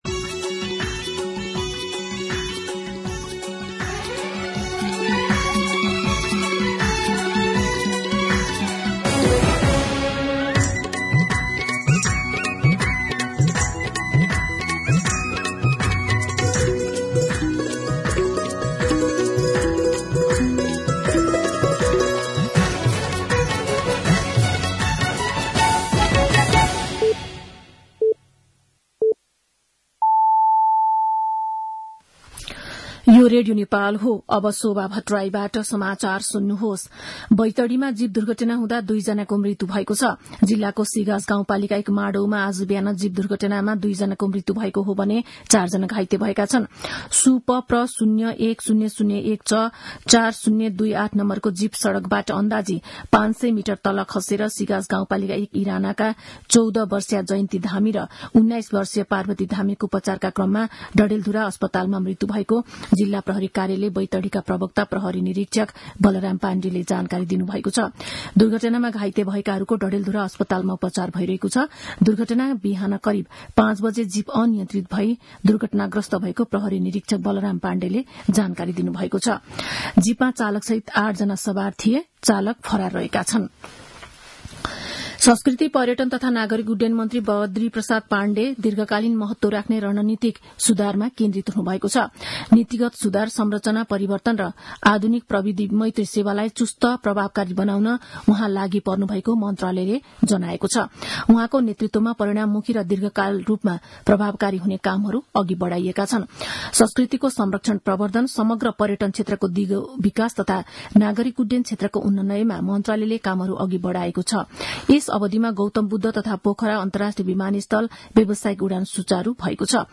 An online outlet of Nepal's national radio broadcaster
मध्यान्ह १२ बजेको नेपाली समाचार : १३ माघ , २०८१